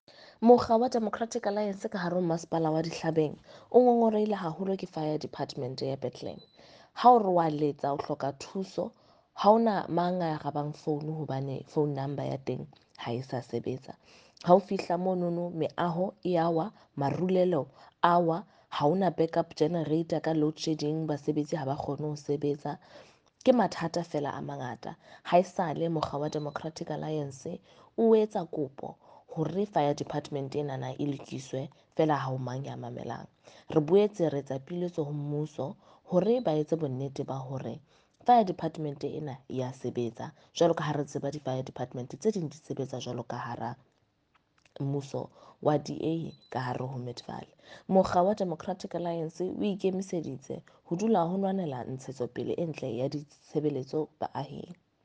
Sesotho by Karabo Khakhau MP.
Sotho-voice-Karabo-3.mp3